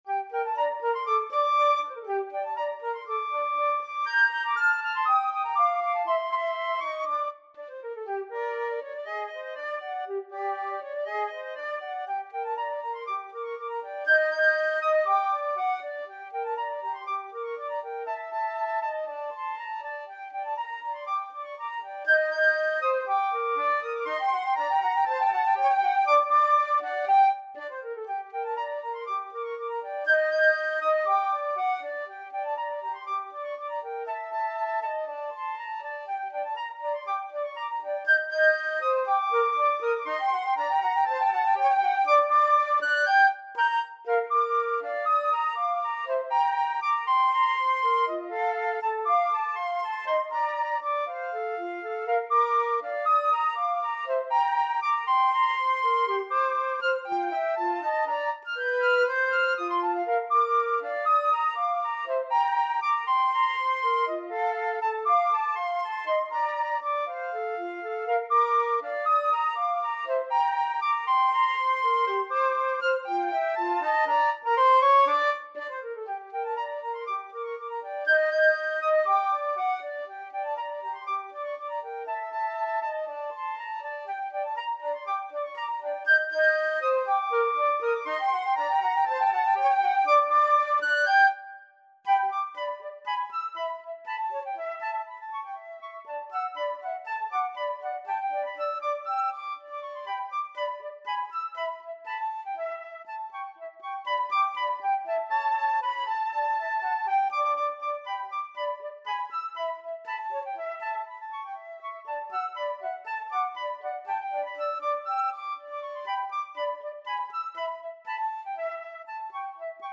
Allegretto Misterioso = 120
2/4 (View more 2/4 Music)
Classical (View more Classical Flute Duet Music)